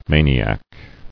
[ma·ni·ac]